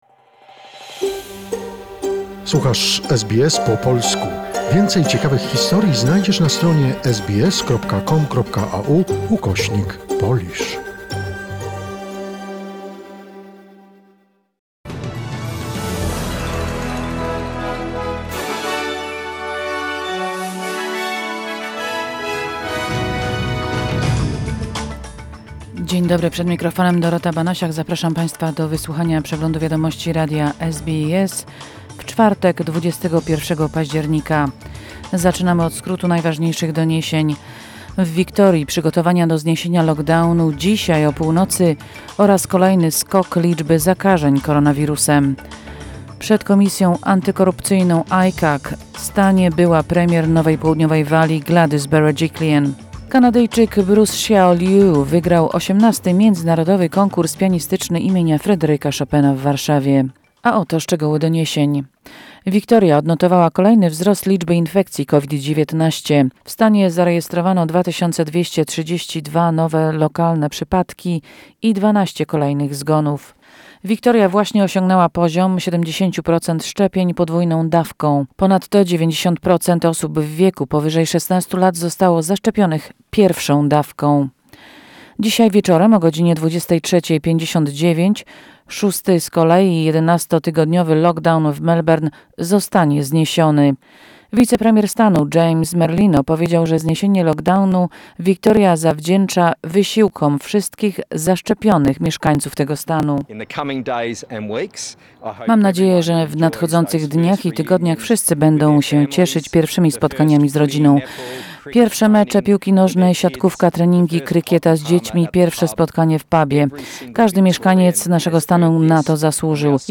SBS News Flash in Polish, 20 October 2021